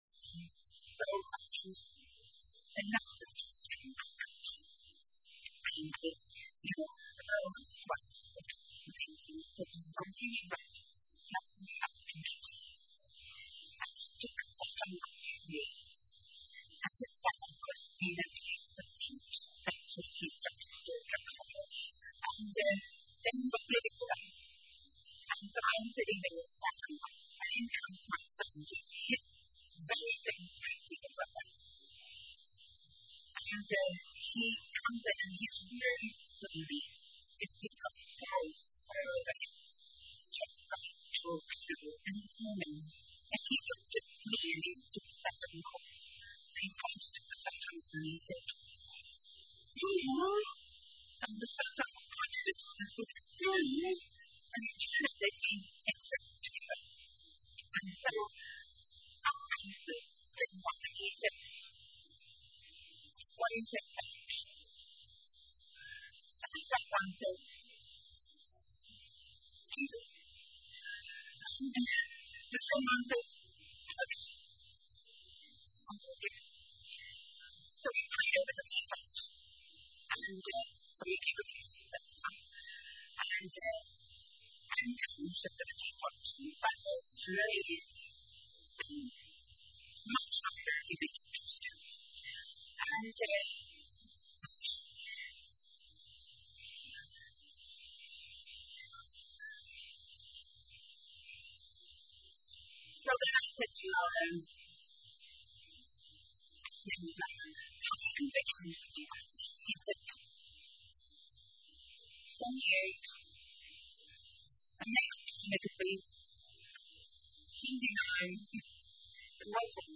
Overall, the sermon encourages gratitude, spiritual growth, and the pursuit of knowledge.